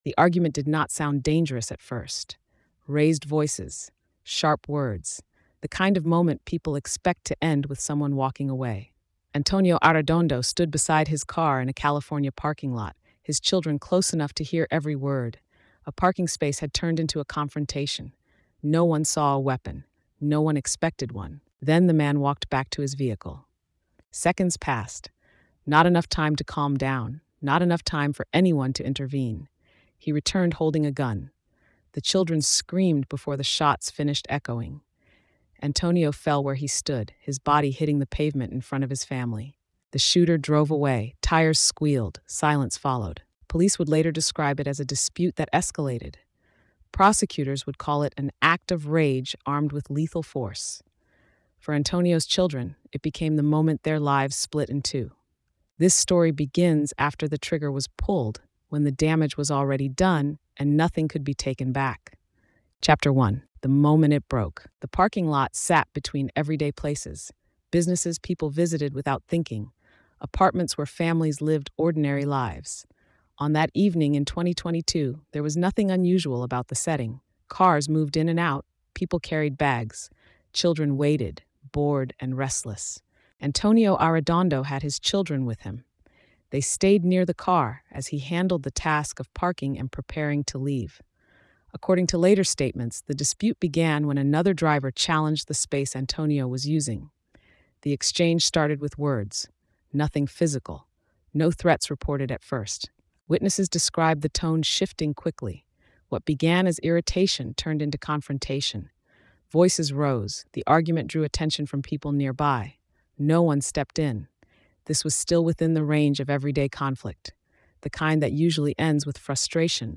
Told in a forensic, pressure driven style, the story traces how an ordinary argument escalated into fatal violence, how investigators reconstructed a chaotic scene, and how the justice system responded to an act that permanently altered a family. The focus remains on evidence, accountability, and the lasting cost carried by those left behind.